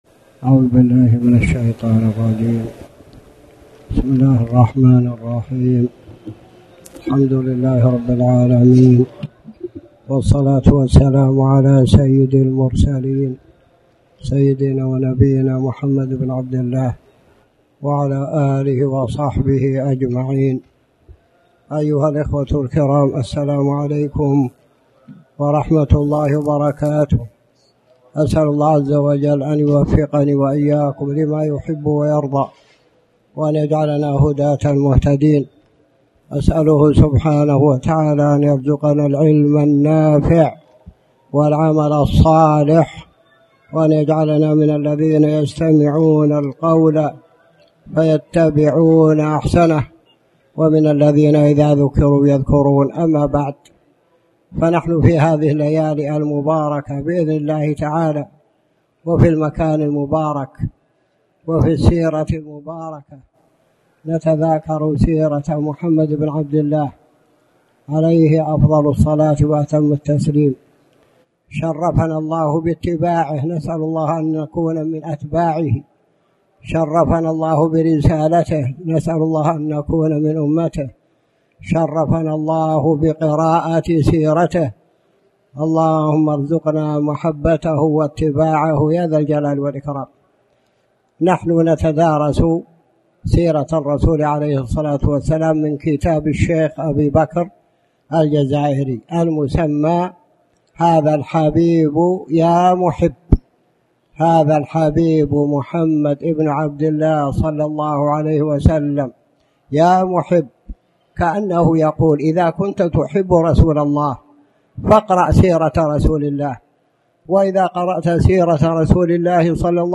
تاريخ النشر ٧ محرم ١٤٣٩ هـ المكان: المسجد الحرام الشيخ